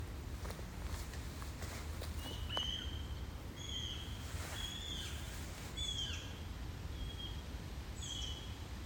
Birds -> Birds of prey ->
Eurasian Sparrowhawk, Accipiter nisus
Administratīvā teritorijaRīga